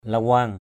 /la-wa:ŋ/